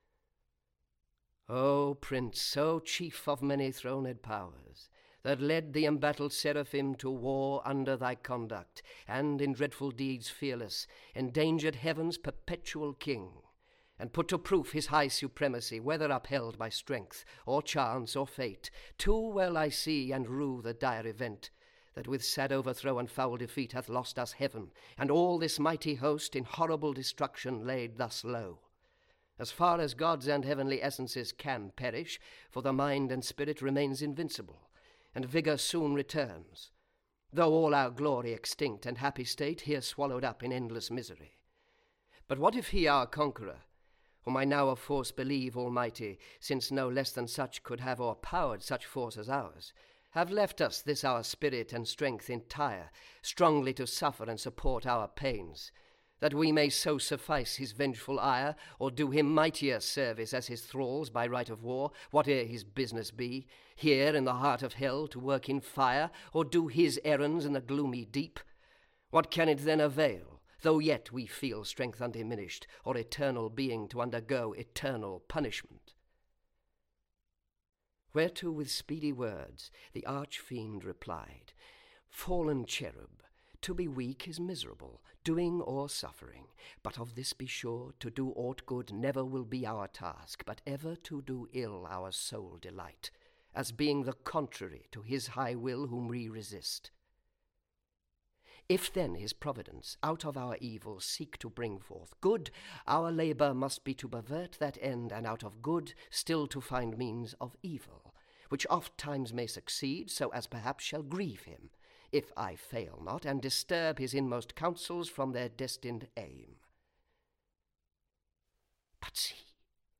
Paradise Lost - John Milton - Hörbuch